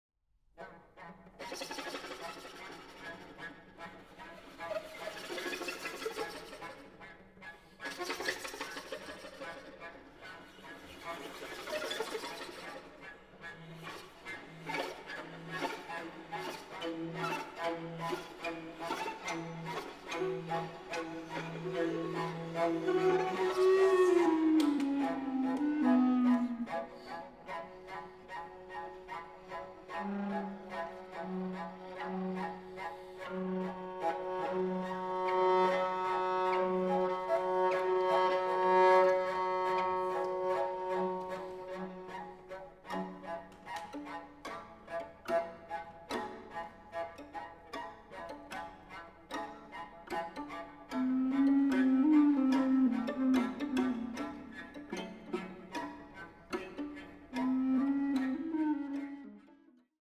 Recorder